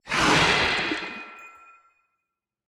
Minecraft Version Minecraft Version latest Latest Release | Latest Snapshot latest / assets / minecraft / sounds / entity / glow_squid / squirt2.ogg Compare With Compare With Latest Release | Latest Snapshot
squirt2.ogg